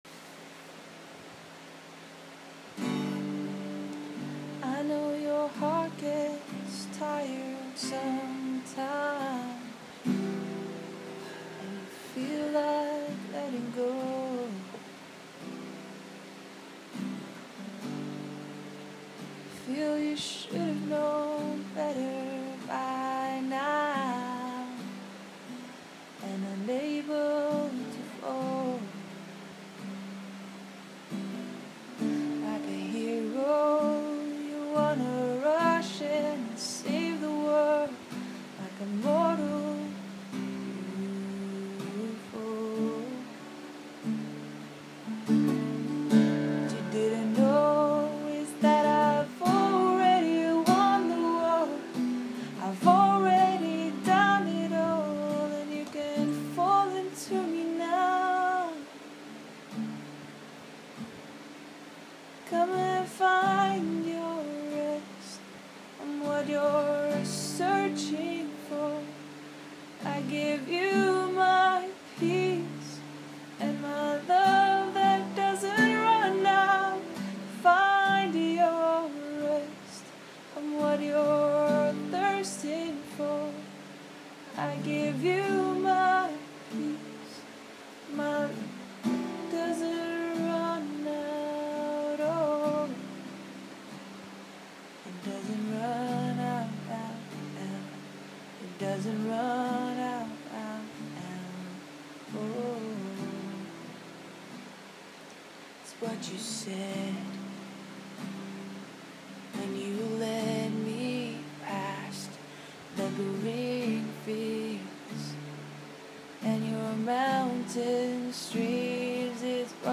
Raw voice note from Jan 2017